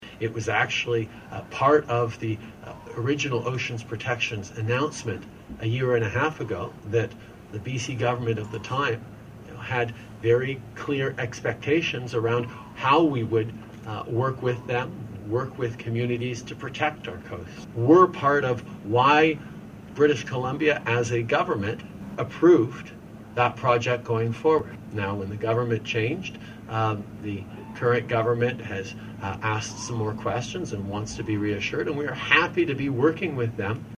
Prime Minister Justin Trudeau speaks on BC Government's environmental concerns
Prime Minister Justin Trudeau was in Prince Rupert today to announce a new collaboration between the Federal Government and First Nations that aims to utilize the unique input of Indigenous peoples to help better protect and manage BC’s coastline.